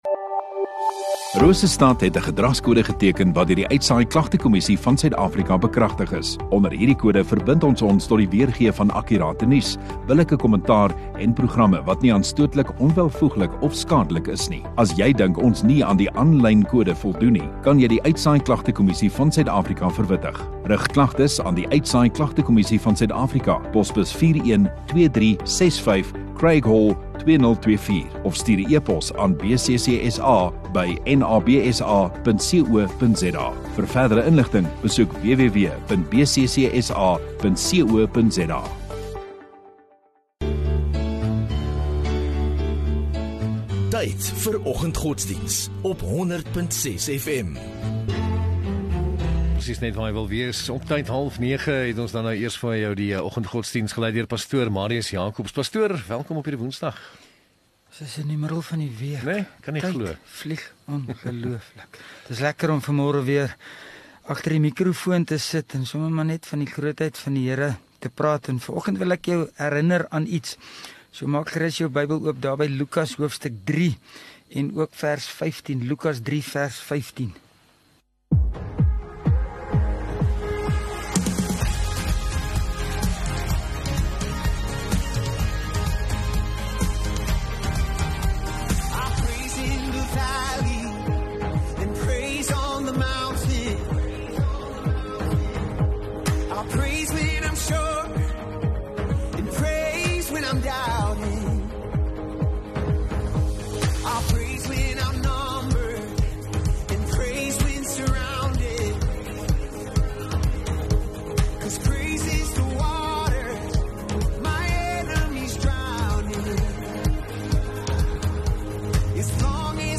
15 May Woensdag Oggenddiens